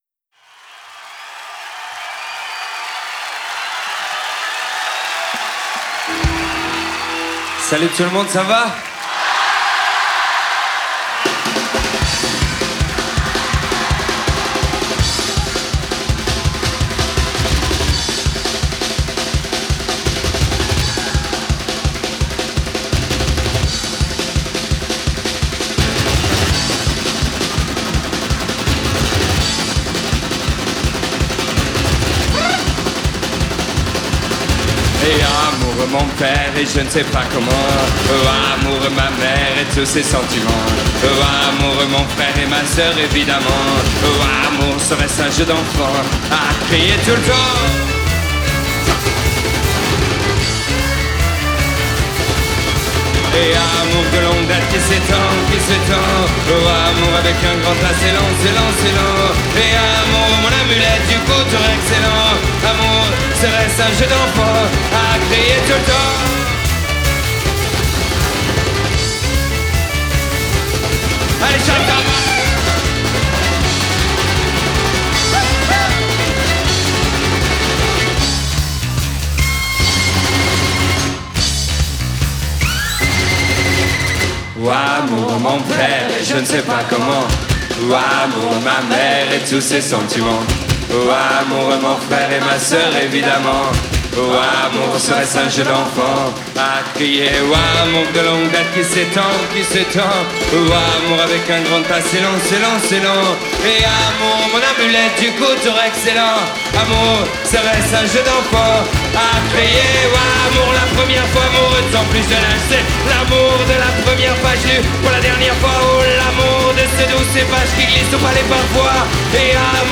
Live à la salle de la Cité, Rennes